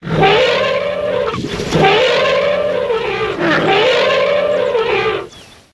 Sonido de elefantes